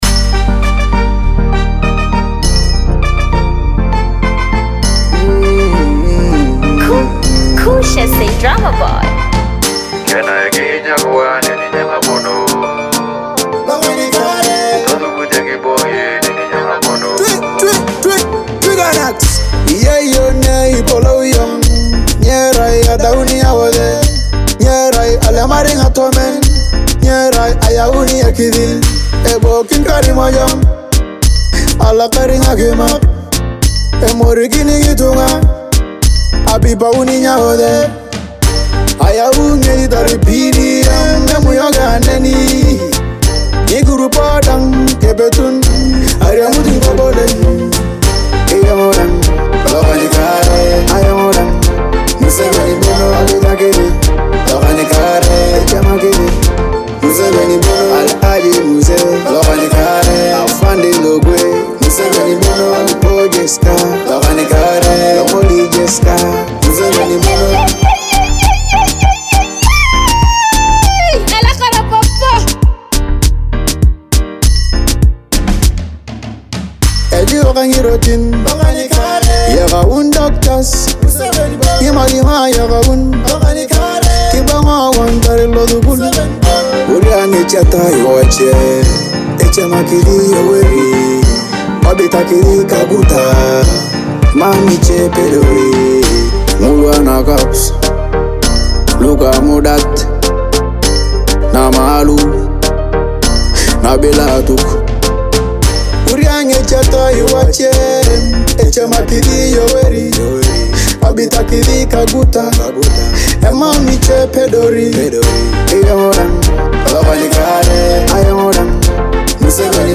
an Afrobeat-Dancehall tribute honoring Uganda’s President.
a compelling Afrobeat and Dancehall fusion track
With energetic beats and meaningful lyrics